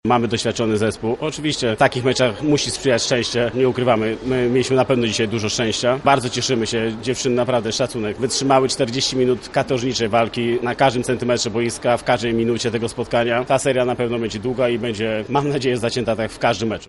trener zespołu gości.